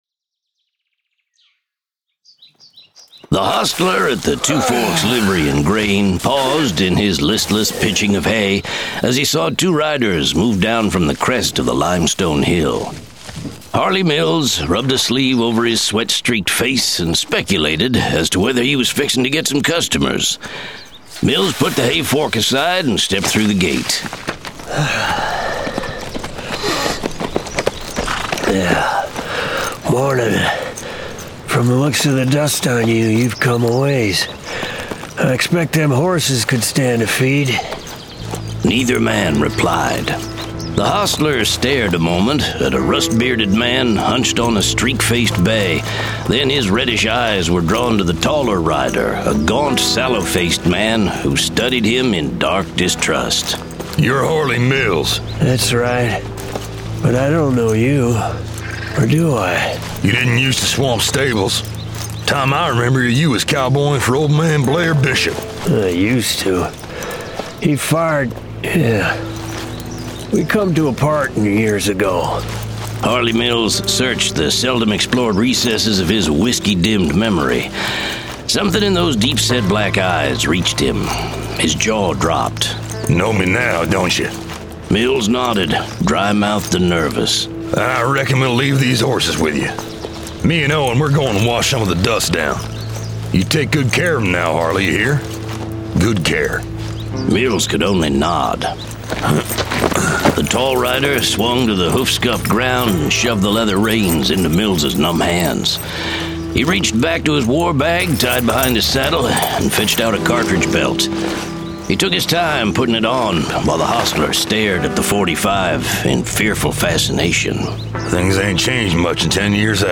Full Cast. Cinematic Music. Sound Effects.
Genre: Western